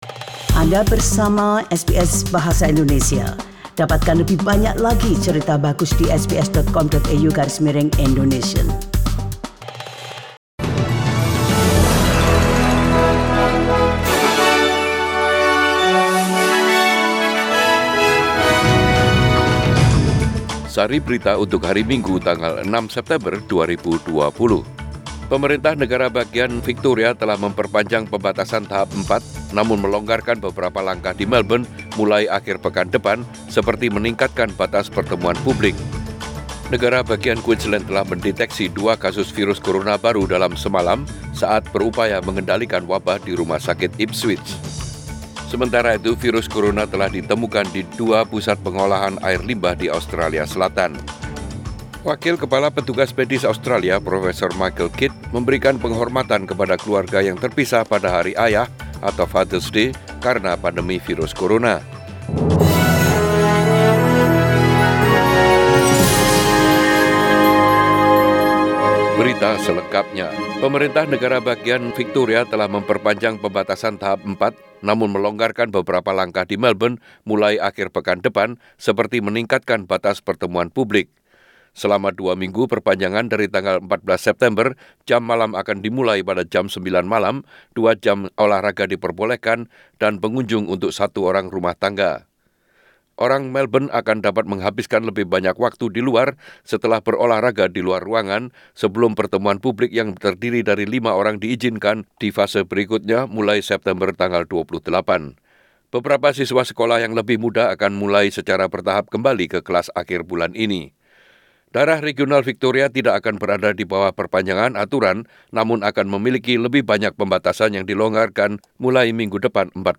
Warta Berita Radio SBS Program Bahasa Indonesia - 06 September 2020